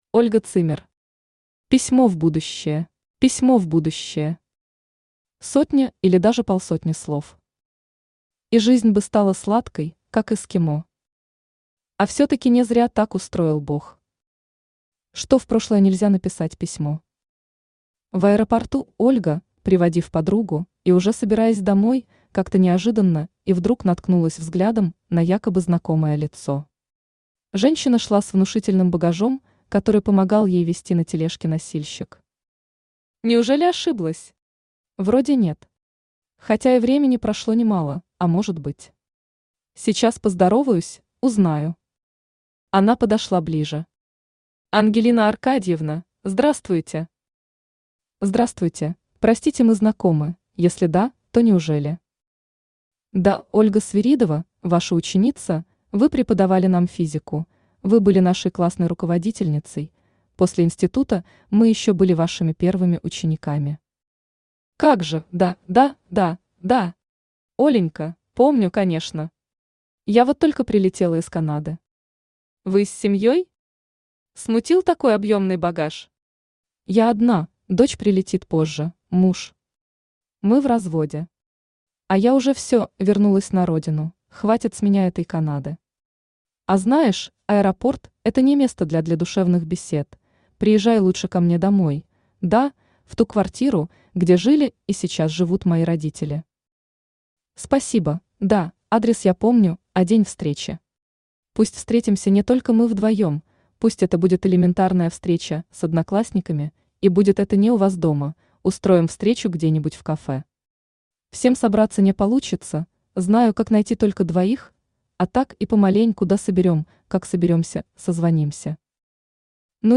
Aудиокнига Письмо в будущее Автор Ольга Zimmer Читает аудиокнигу Авточтец ЛитРес.